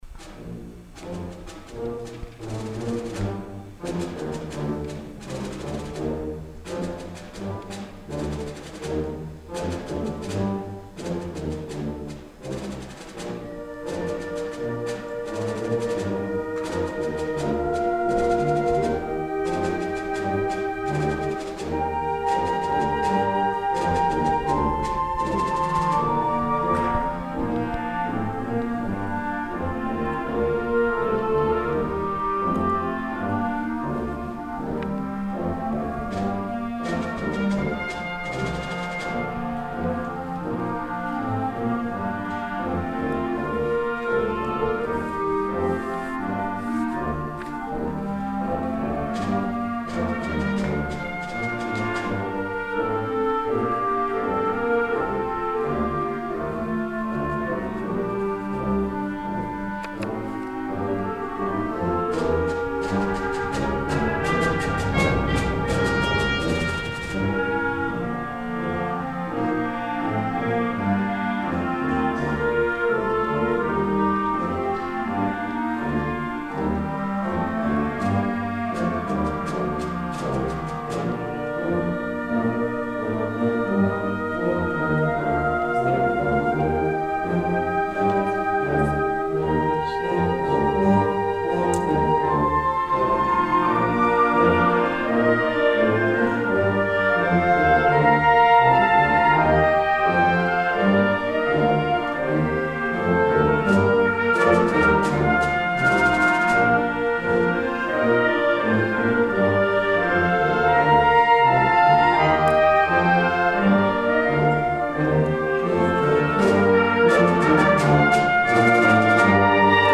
The program was held on Sunday afternoon, March 18, at the Decker Auditorium on the campus of Iowa Central Community College.   This was the band’s traditional Irish Concert in celebration of St. Patrick’s Day.
Listen to the band perform Leroy Anderson's "The Minstrel Boy."